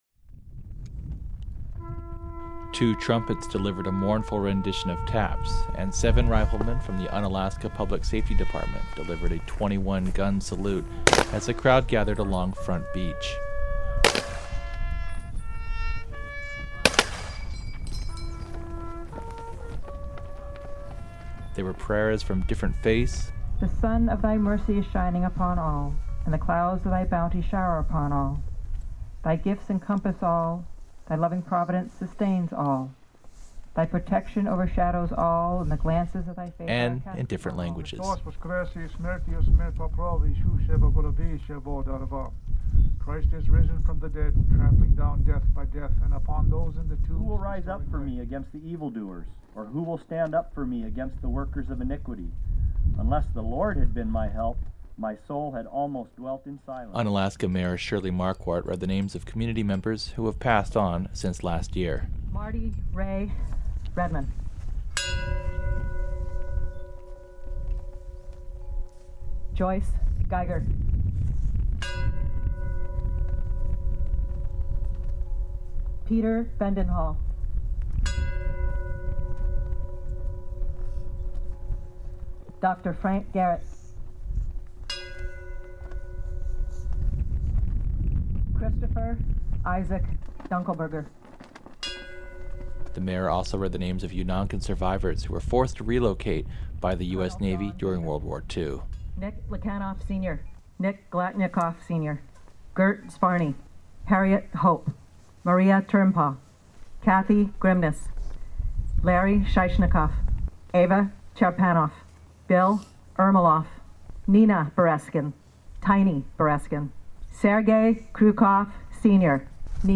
Unalaska, AK – About a hundred people gathered in the wind and drizzle to honor men and women who had died in the armed forces. Words were also said for mariners lost at sea and community members who had passed away since last year. All this came during Unalaska's Memorial Day Service held yesterday in Memorial Park. KUCB brings you this report.